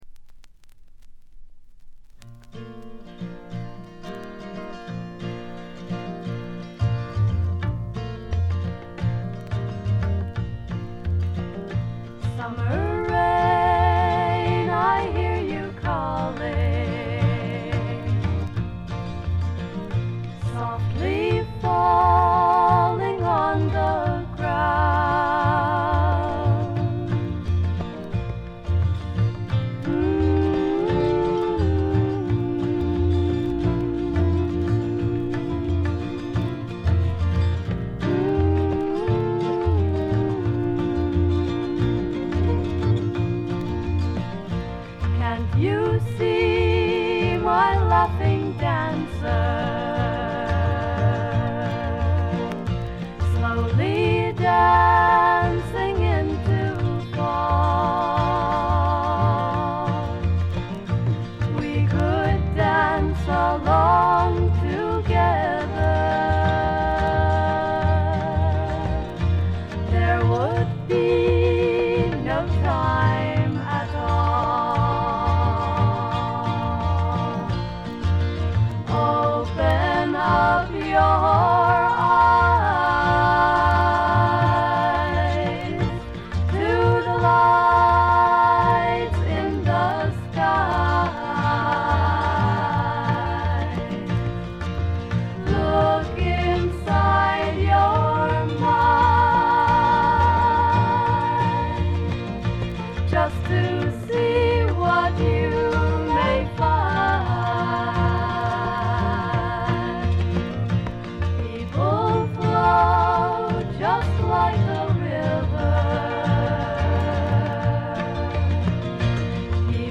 静音部での軽微なチリプチ程度。
甘酸っぱい香りが胸キュンのまばゆいばかりの青春フォークの傑作。
試聴曲は現品からの取り込み音源です。
Vocals, Guitar, Composed By ?